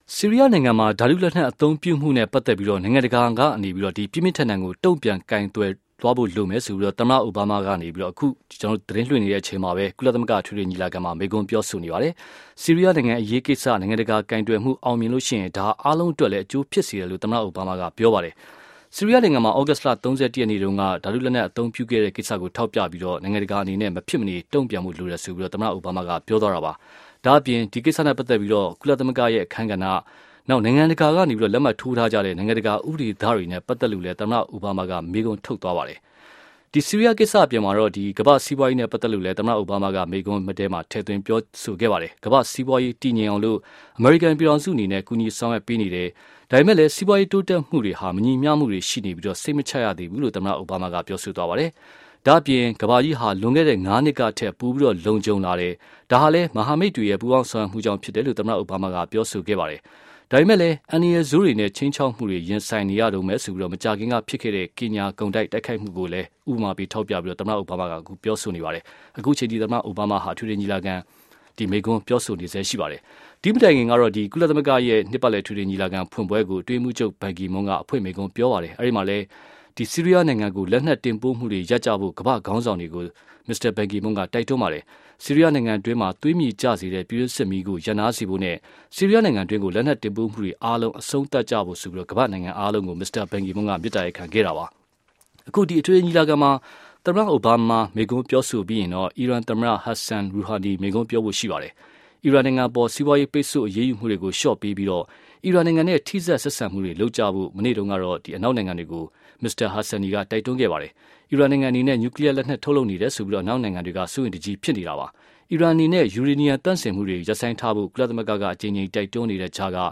အိုဘားမား မိန့်ခွန်း